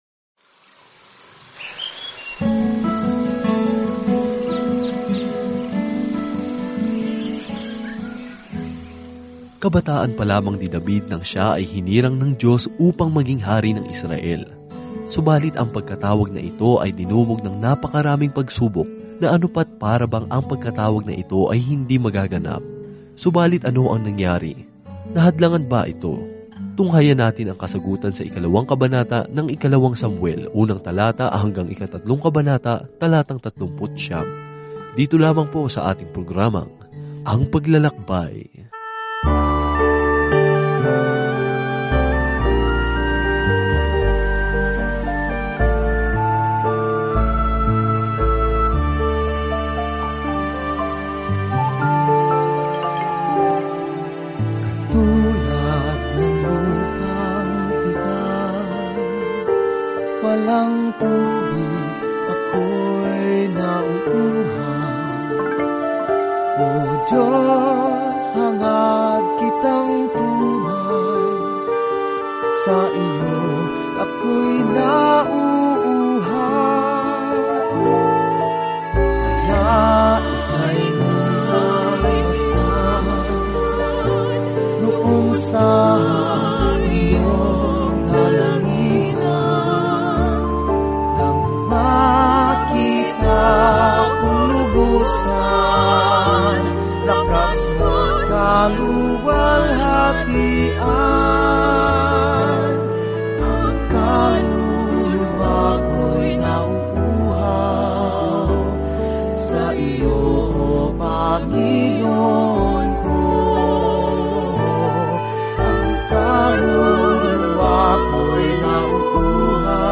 Banal na Kasulatan 2 Samuel 2 2 Samuel 3 Umpisahan ang Gabay na Ito Araw 2 Tungkol sa Gabay na ito Ang kuwento ng relasyon ng Israel sa Diyos ay nagpapatuloy sa pagpapakilala ng mga propeta sa listahan kung paano nakikipag-ugnayan ang Diyos sa kanyang mga tao. Araw-araw na paglalakbay sa 2 Samuel habang nakikinig ka sa audio study at nagbabasa ng mga piling talata mula sa salita ng Diyos.